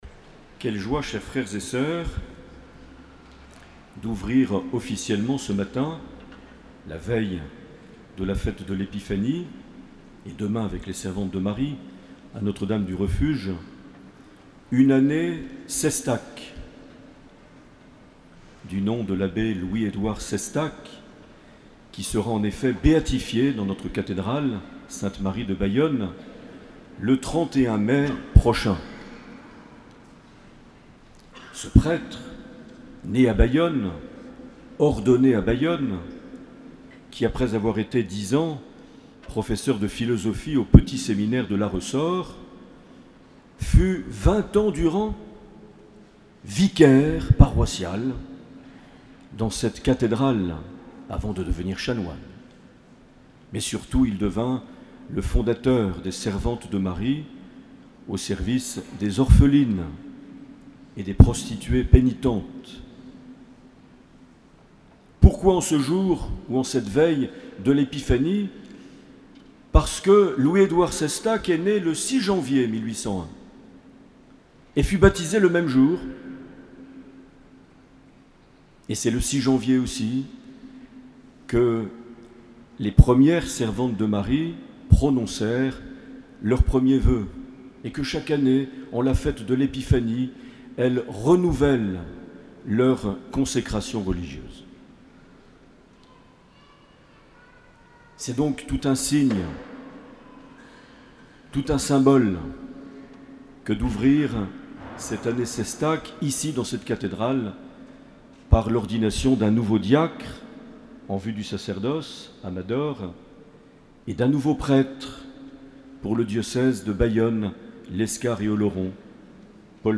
3 janvier 2015 - Cathédrale de Bayonne
Accueil \ Emissions \ Vie de l’Eglise \ Evêque \ Les Homélies \ 3 janvier 2015
Une émission présentée par Monseigneur Marc Aillet